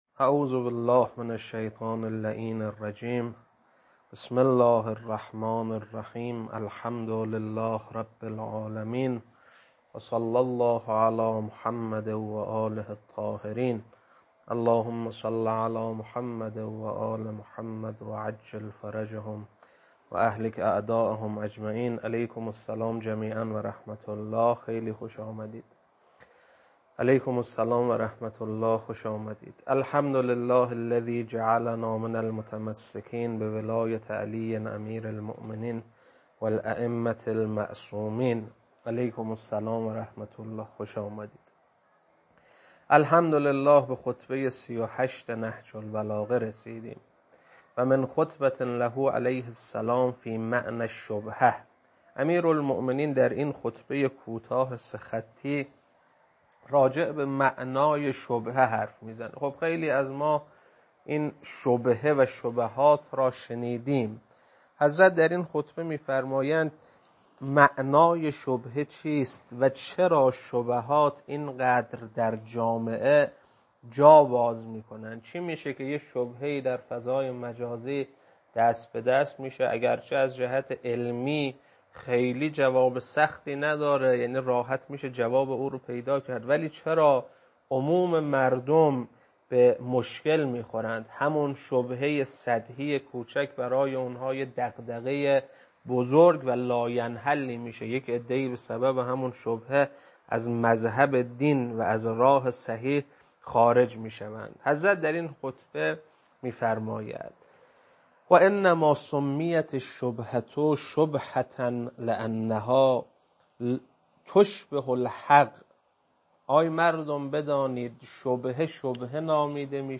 خطبه 38.mp3